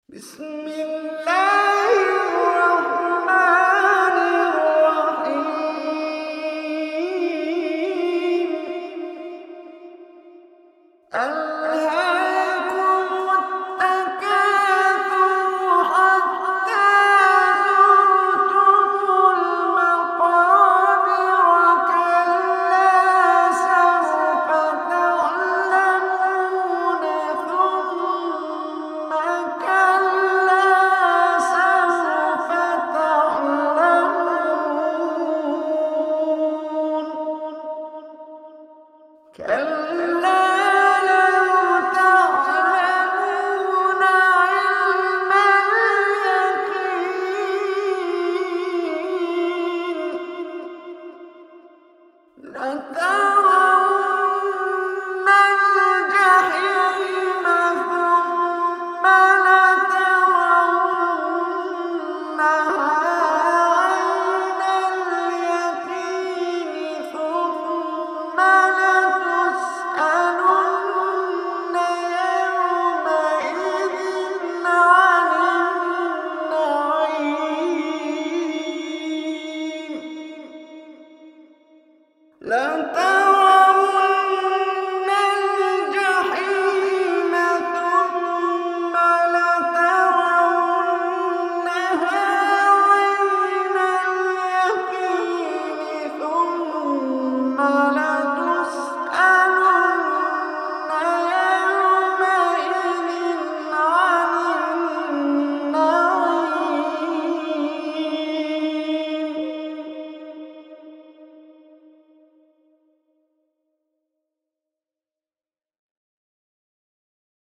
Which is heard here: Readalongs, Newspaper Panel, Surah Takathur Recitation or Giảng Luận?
Surah Takathur Recitation